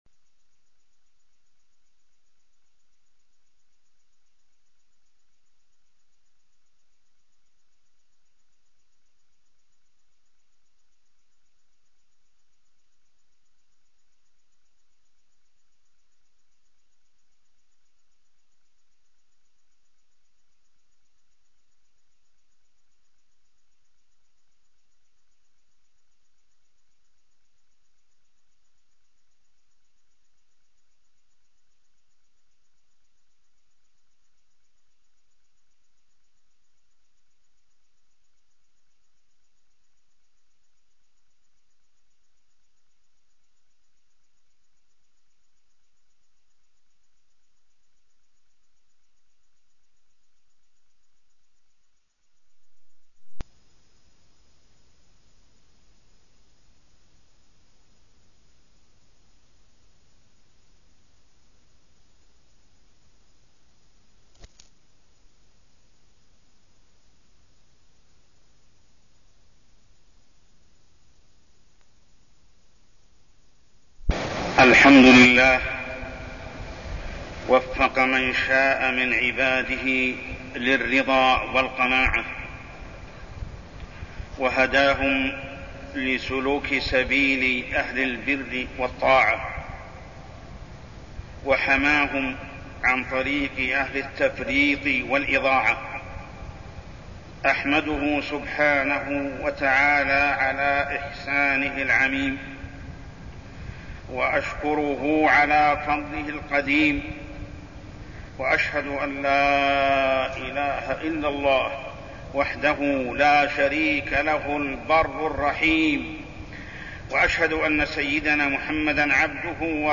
تاريخ النشر ٤ رجب ١٤١٤ هـ المكان: المسجد الحرام الشيخ: محمد بن عبد الله السبيل محمد بن عبد الله السبيل تحريم الكذب